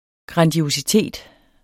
Udtale [ gʁɑndiosiˈteˀd ]